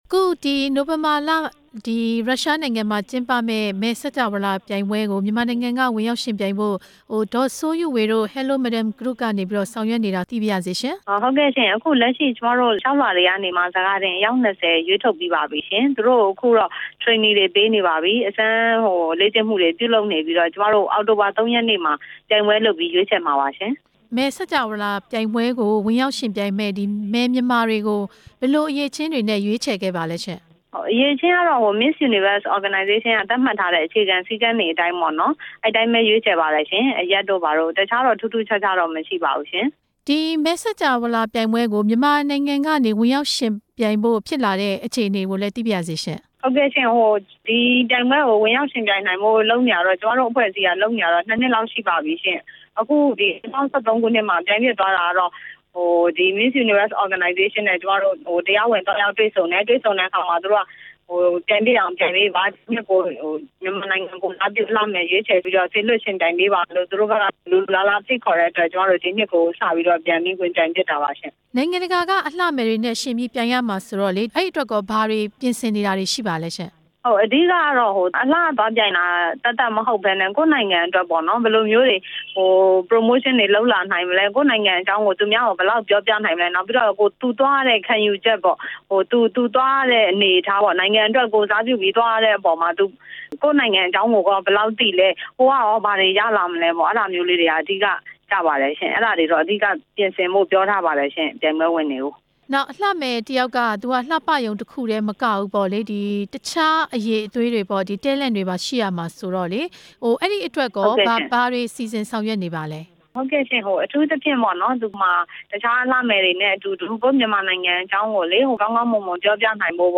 မယ်စကြာဝဠာပြိုင်ပွဲ ဝင်ရောက်ယှဉ်ပြိုင်ရေးနဲ့ ပတ်သက်တဲ့ ဆက်သွယ်မေးမြန်းချက်